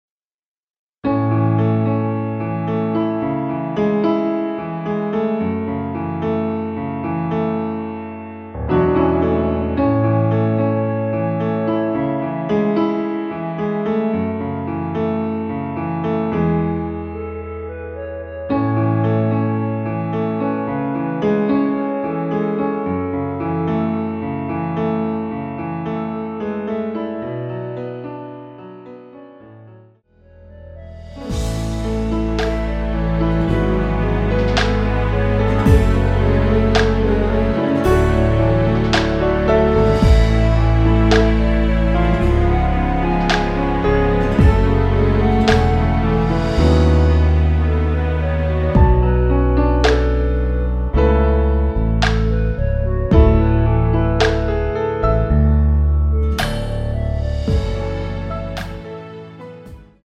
(-1) 멜로디 포함된 MR 입니다.(미리듣기 참조)
앞부분30초, 뒷부분30초씩 편집해서 올려 드리고 있습니다.
중간에 음이 끈어지고 다시 나오는 이유는